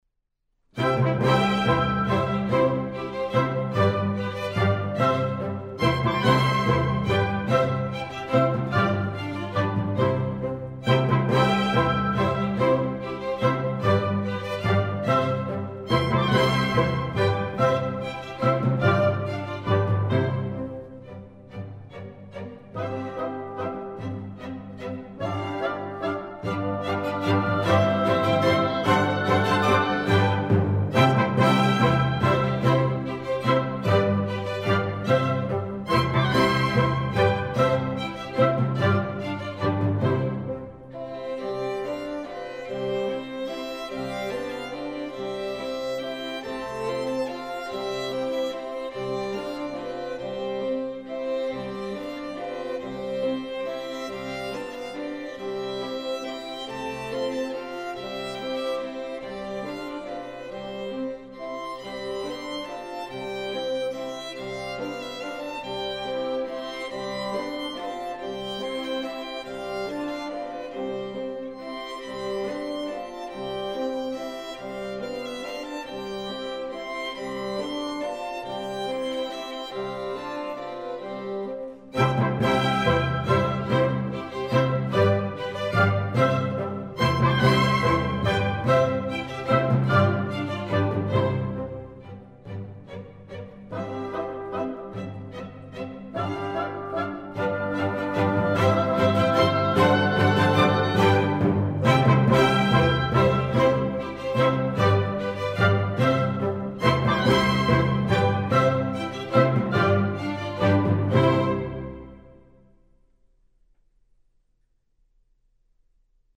Im mittleren Teil wird die Leyer imitiert, die bei privaten Tanzbelustigungen oft das einzige Instrument war und dem Klangbild der Musik bei einem 'Volksball' ziemlich nahe kommen dürfte.